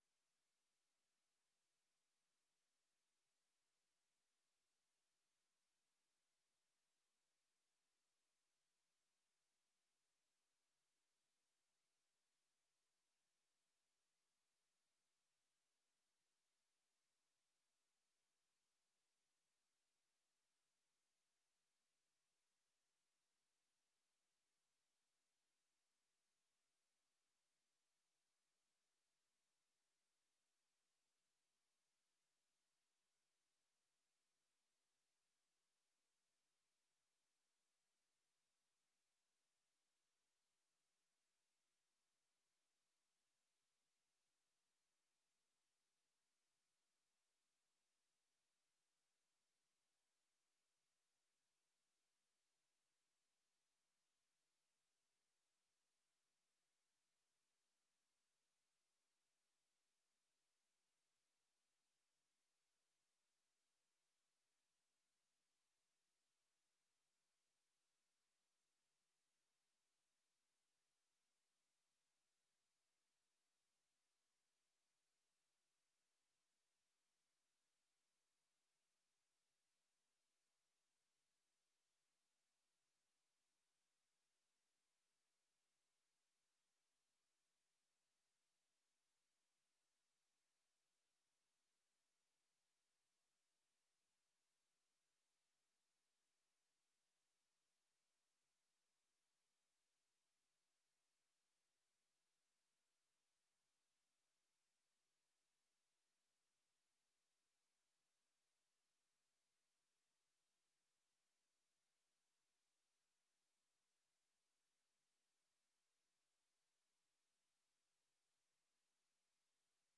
Gemeenteraad 21 juli 2022 19:30:00, Gemeente Roosendaal
Om 19.30 uur begint de inspraakbijeenkomst. Aansluitend is een gemeenteraadsvergadering.
Locatie: Raadzaal